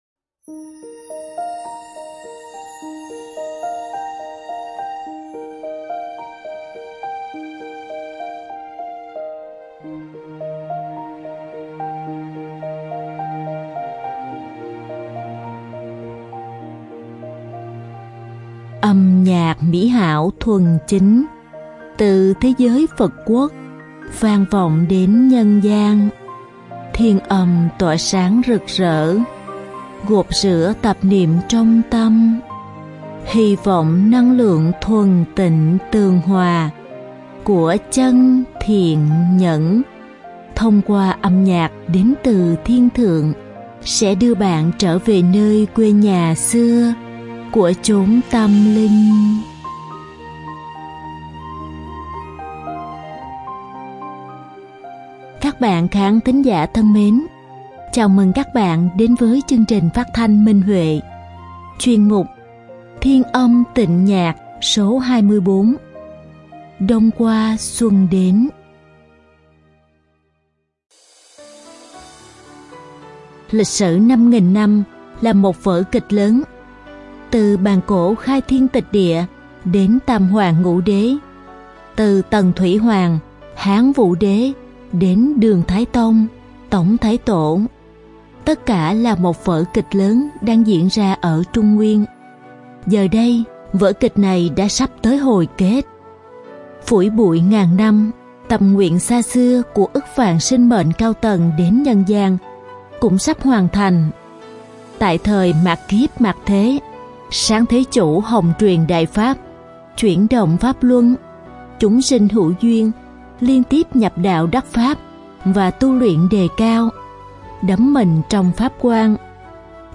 Đơn ca nam
Màn diễn tấu sáo trúc
Đơn ca nữ